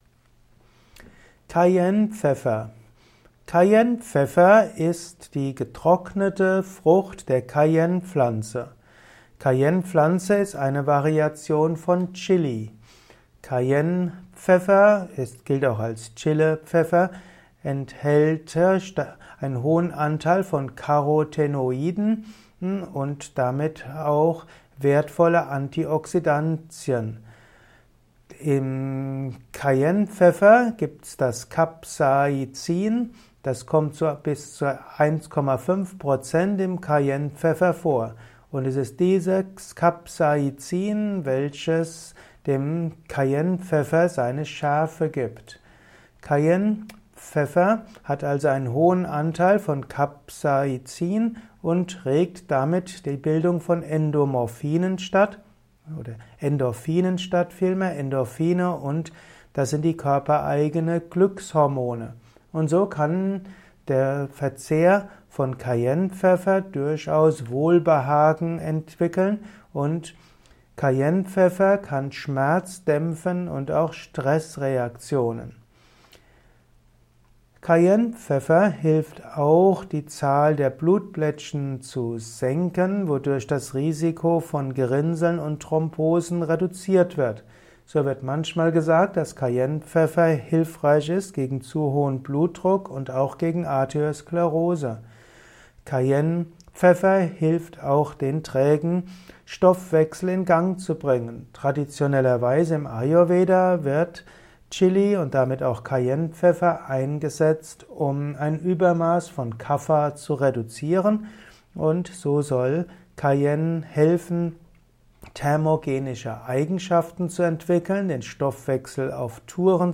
Kurzvortrag